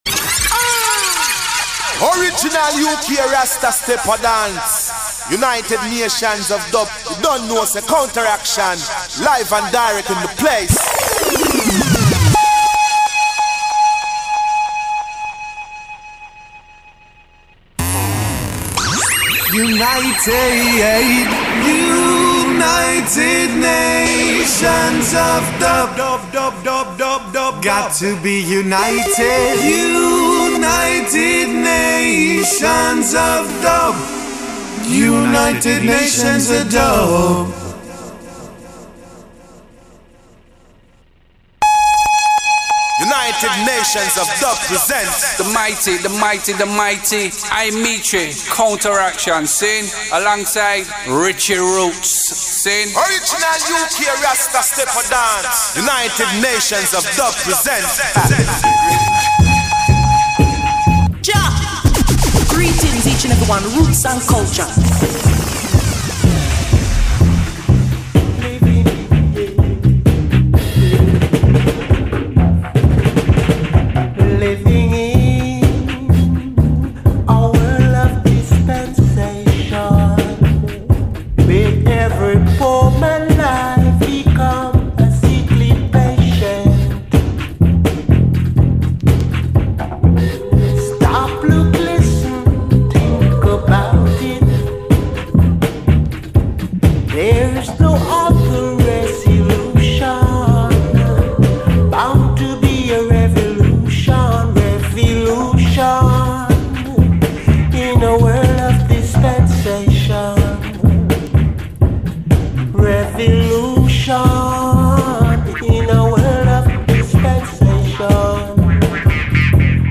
Radio DUBcast